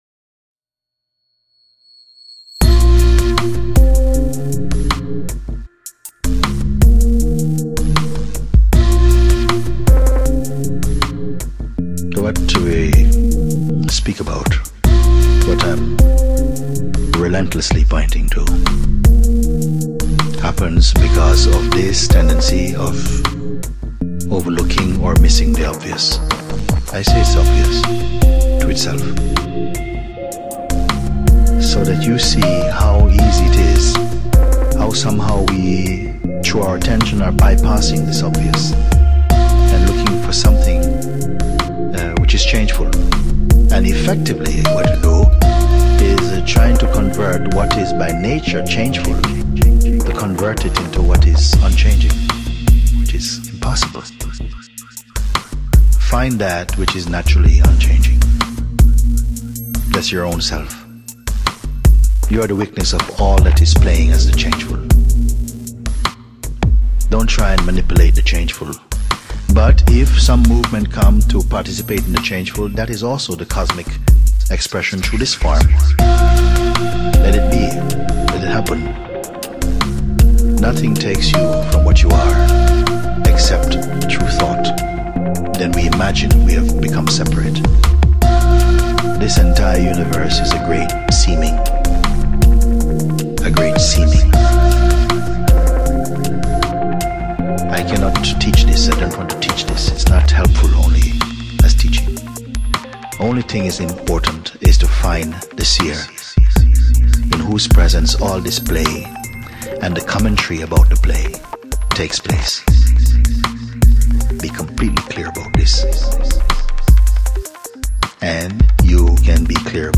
ambient-electro compositions